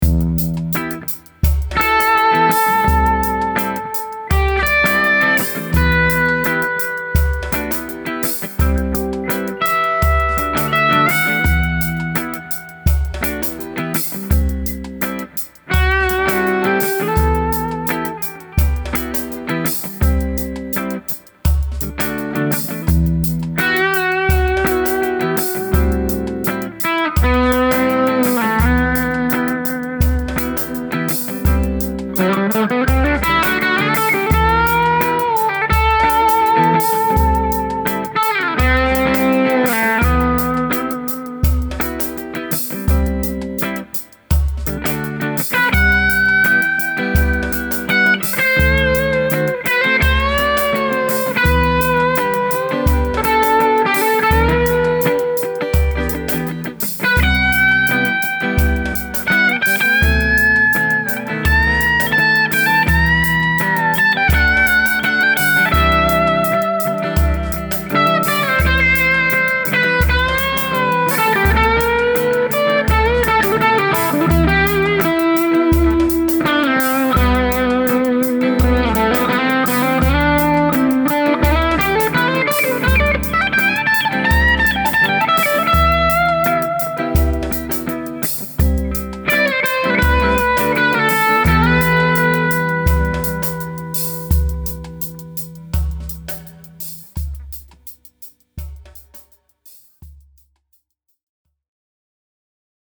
It had a crying-like quality to it that literally stunned me.
The sound that this pedal creates is simply incredible.
When I got up above the 14th fret, I couldn’t believe how the pedal created this wailing sound.
Anyway, I know that I’ve mentioned that this pedal is fantastic standalone, but in this case, I actually used it to help drive my DV Mark Little 40, so the breakup that you hear is a combination of the clipping of the pedal with the clipping of the front-end of my amp.
The volume on the pedal is set to about 11am, with the drive between 2 and 3 pm. Bass and Treble are both at about 1pm.